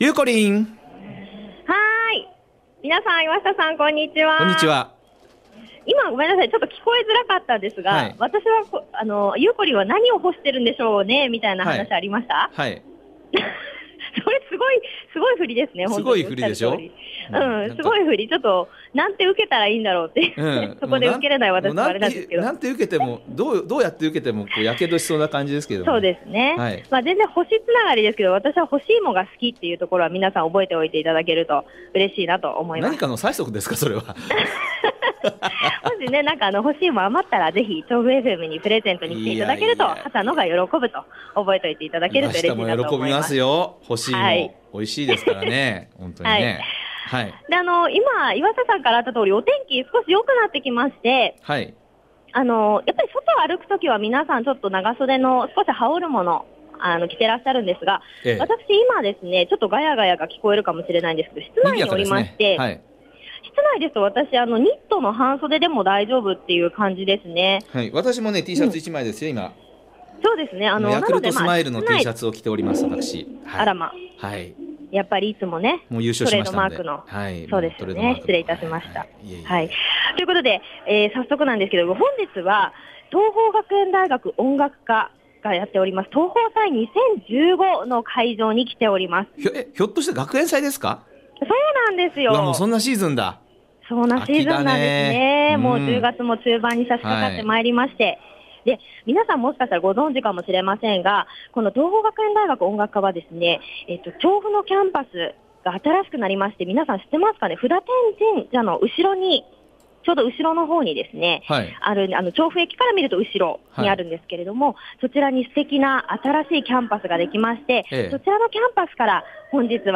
★びゅーサン 街角レポート
本日のびゅーサン街角レポートは、 調布の新キャンパスで開催中の桐朋祭2015にお邪魔してきましたよ～♪ 仙川から調布にキャンパスが移動したの、皆さんご存知でしたか～？